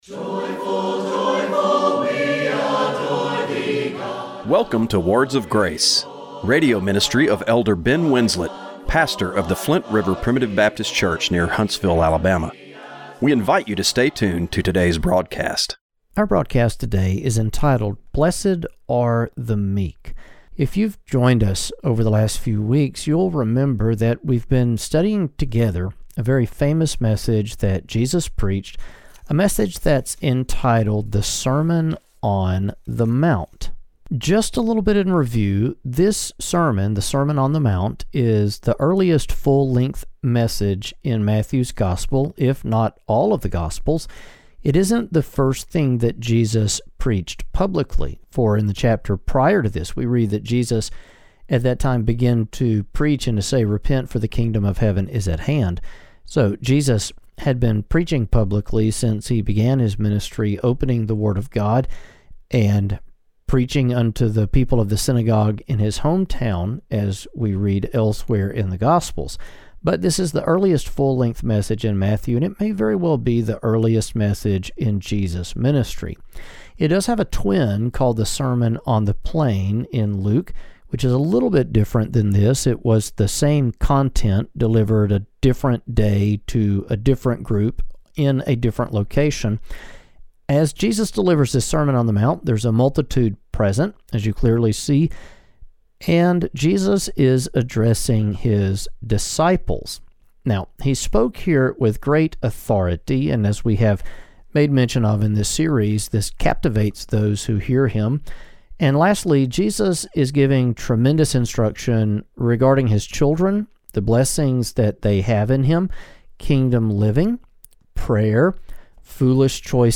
Radio broadcast February 16, 2025.